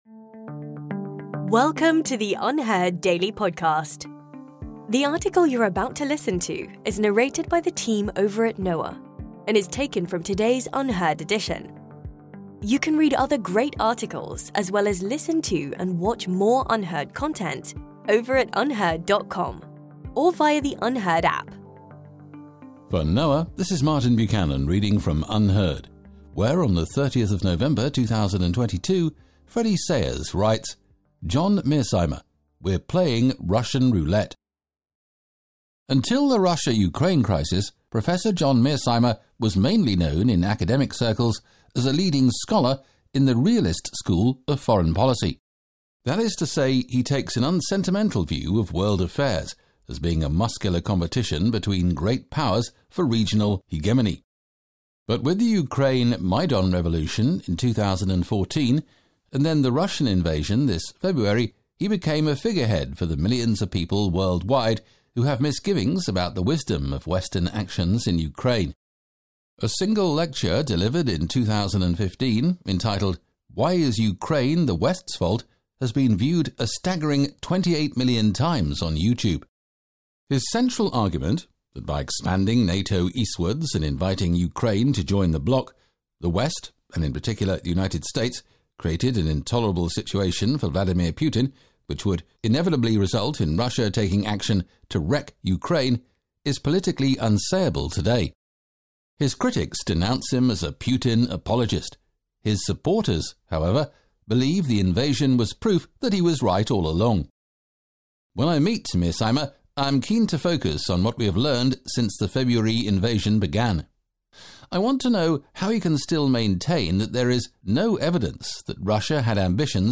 In today's episode, Freddie Sayers interviews John Mearsheimer about the West and how it is screwed according to the realist foreign policy scholar, in an UnHerd exclusive interview titled John Mearsheimer: We’re playing Russian roulette.